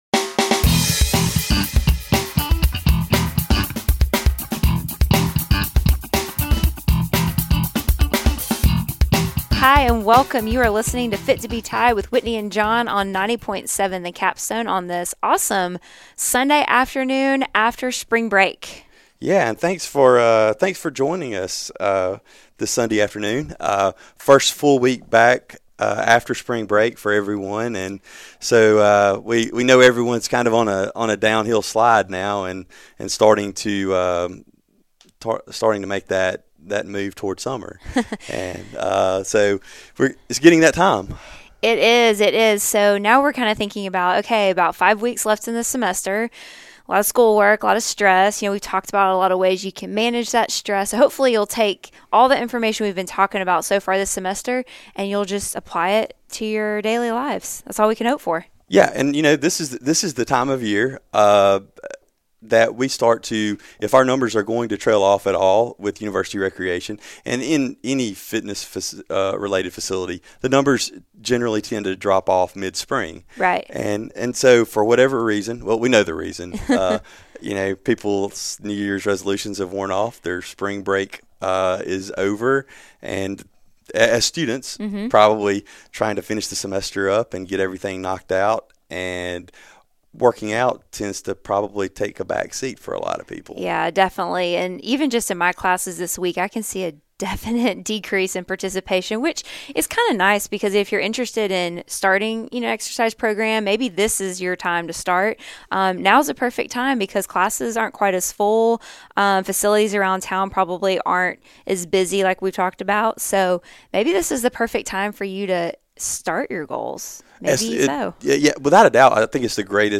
Originally aired 03/27/2016 on WVUA 90.7 FM, Tuscaloosa, AL.